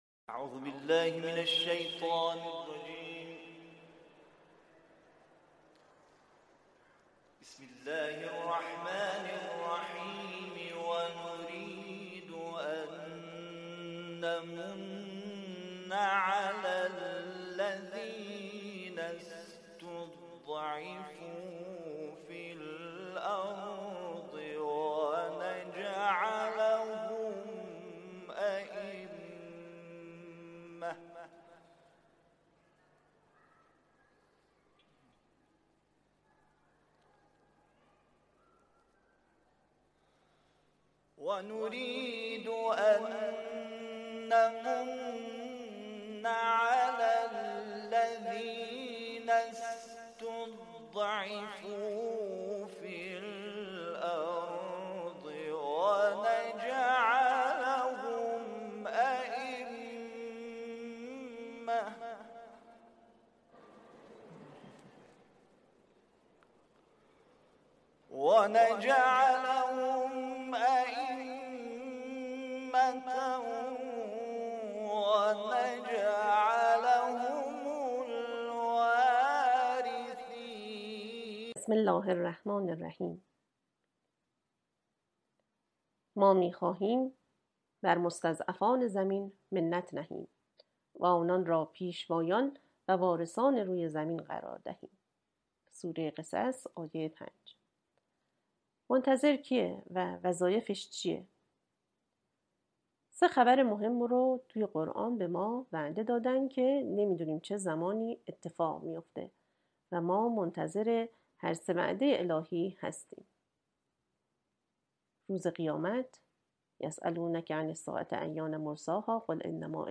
سال انتشار: پخش دریافت اشتراک گذاری 6 Like 10:35 0 توضیحات بسمه تعالی متن سخنرانی وظایف منتظران امام زمان (عج)- قسمت اول " ما می‌خواهیم بر مستضعفان زمین منّت نهیم و آنان را پیشوایان و وارثان روی زمین قرار دهیم !